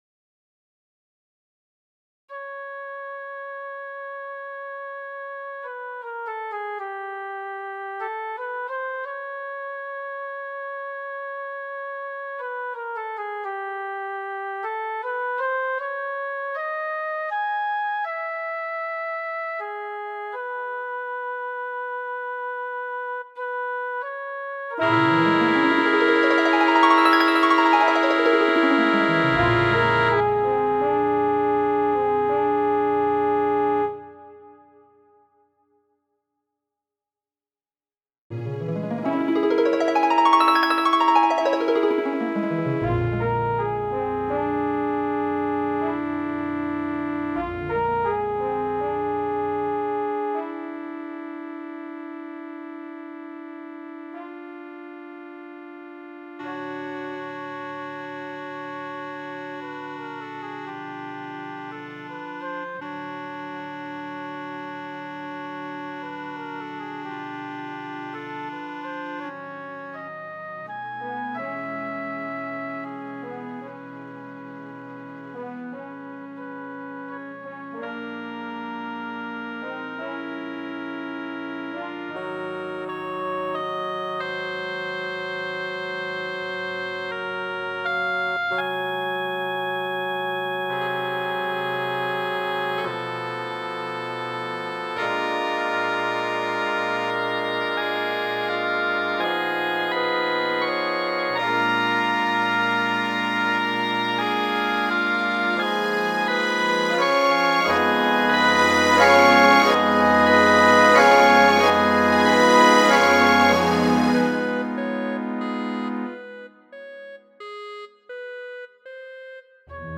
Prelego 2-a de la 3-a Kongreso de HALE (1-a virtuala).